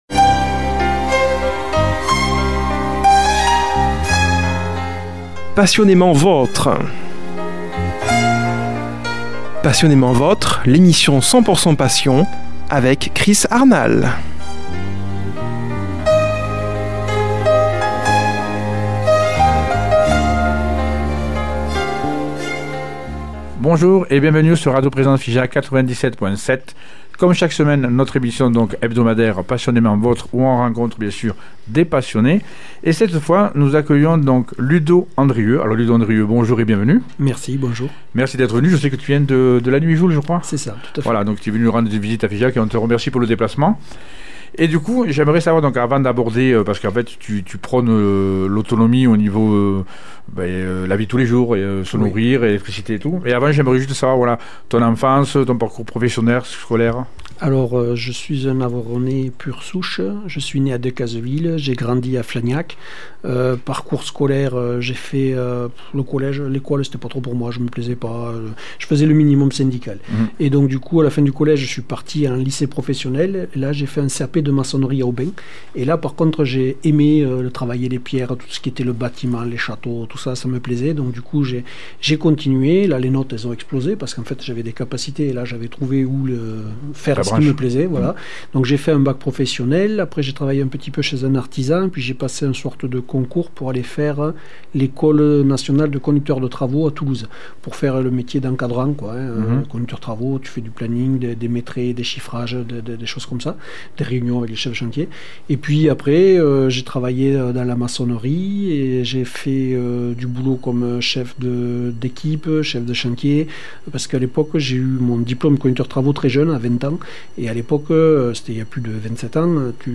reçoit au studio comme invité